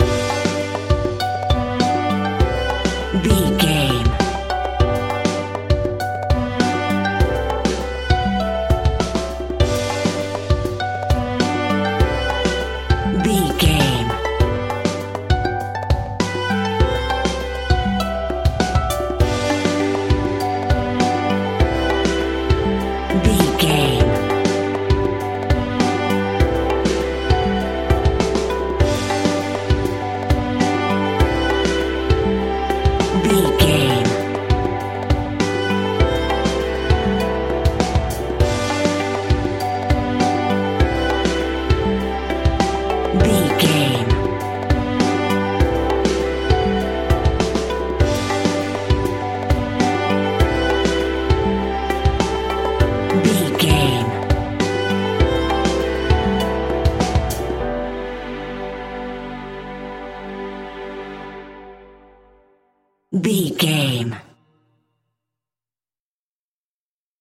Ionian/Major
pop
pop rock
indie pop
fun
energetic
uplifting
motivational
cheesy
cute
instrumentals
upbeat
uptempo
rocking
groovy
guitars
bass
drums
piano
organ